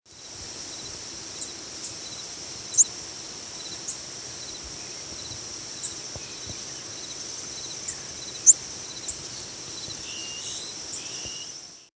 Prothonotary Warbler diurnal flight calls
Bird in flight with Red-winged Blackbird and Eastern Towhee calling in the background.